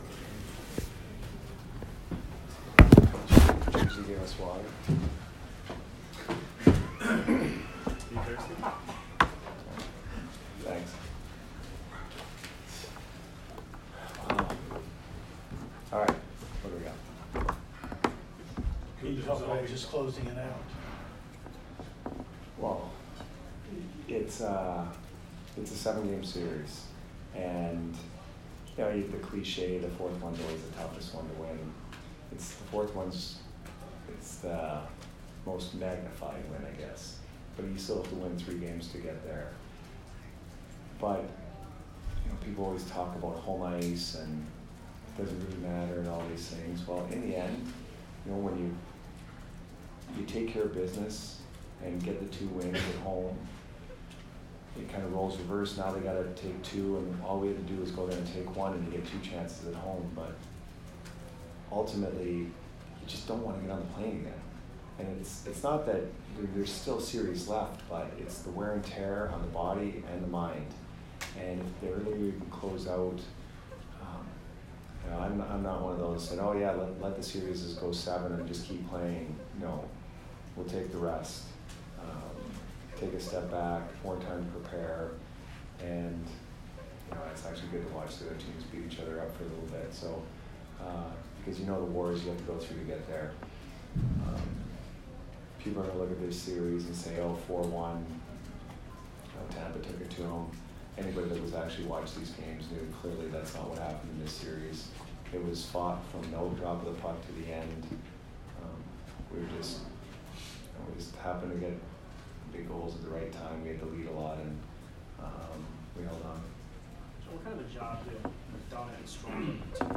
Jon Cooper post-game 4/21